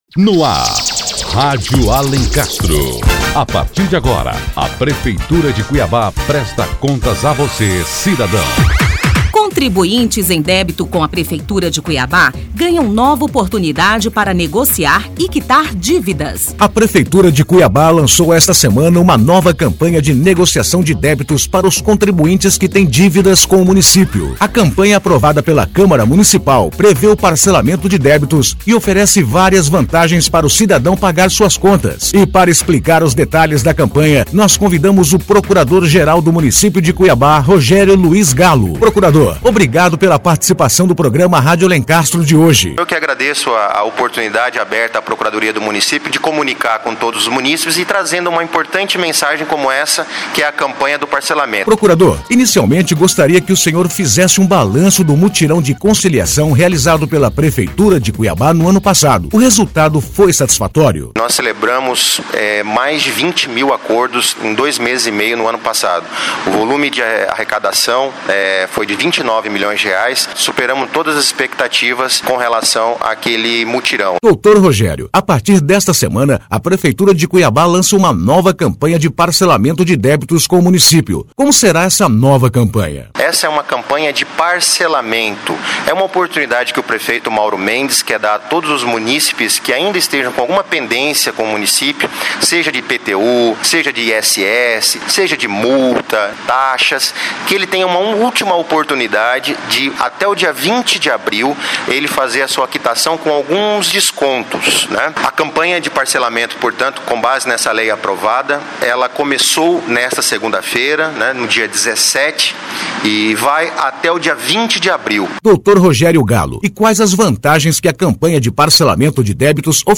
Ouça a entrevista feita com o Procurador-Geral do Município de Cuiabá, Rogério Gallo, e saiba mais sobre esta nova oportunidade.